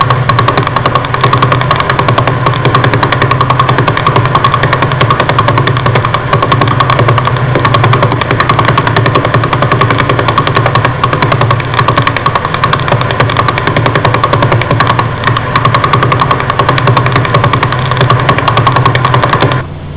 pulsazione”.
pulsar.au